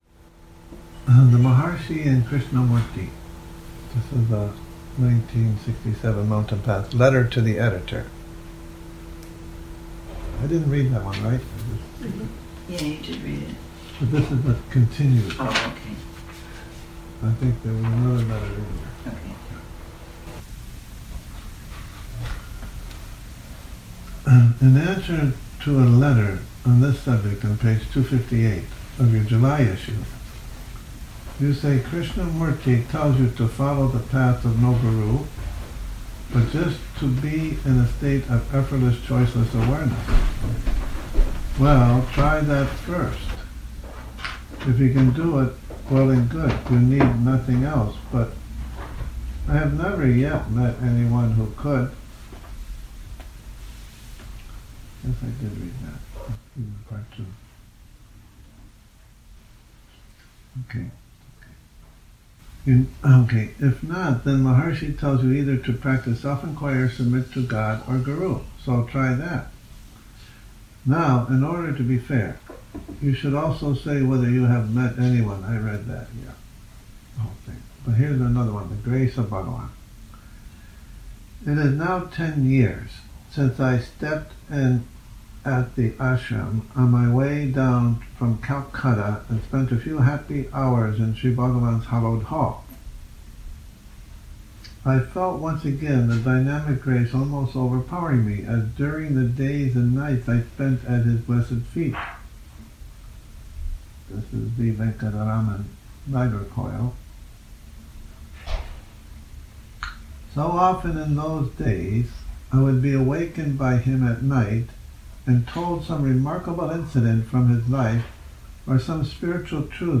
Morning Reading, 24 Nov 2019
a reading from the July 1967 issue of 'The Mountain Path', pp.224-240,